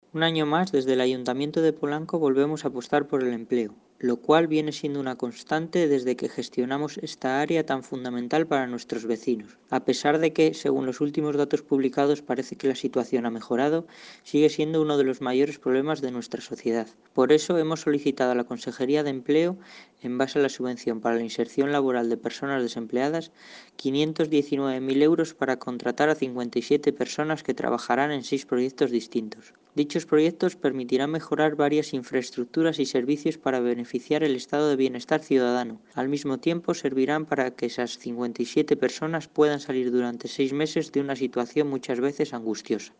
Concejal-empleo-sobre-solicitud-de-programas-Corporaciones-Locales.mp3